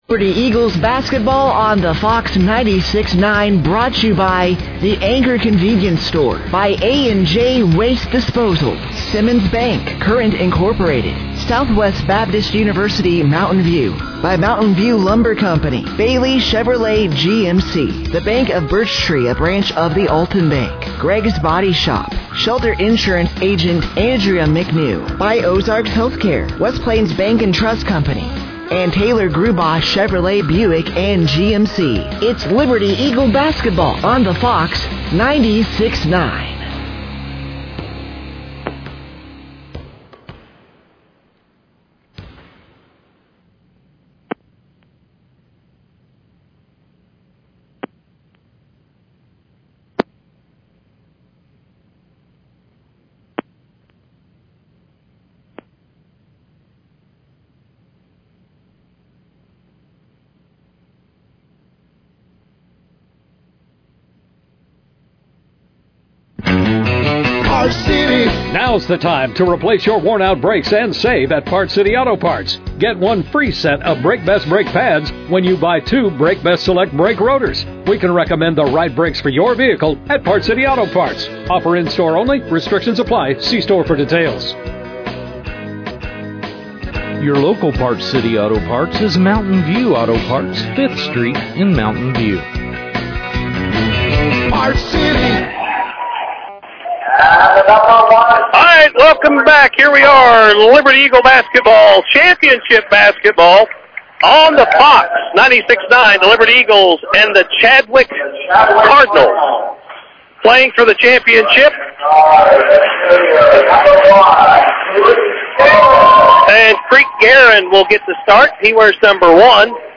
Liberty-Eagles-vs.-Chadwick-Cardinals-Pleasant-Hope-Tournament-Championship-1-17-26.mp3